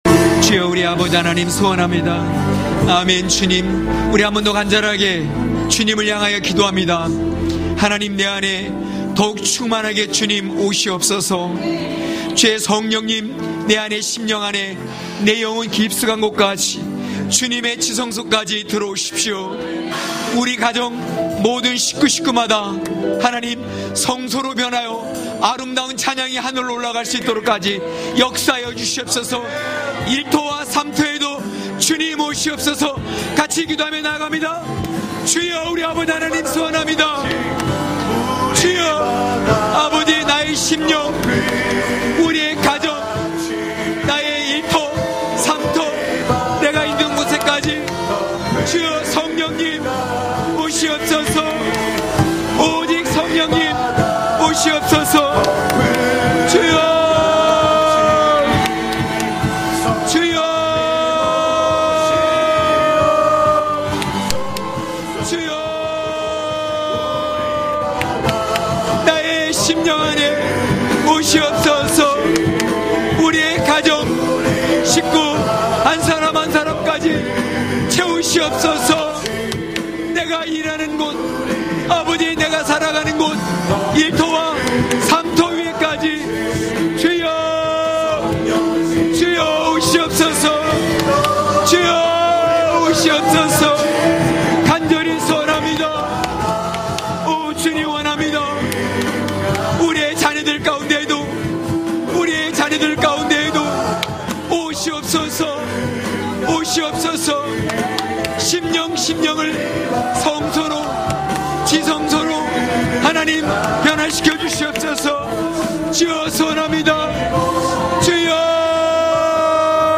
강해설교 - 22.찬양하는 자로 일어서기!!(느13장10~14절).mp3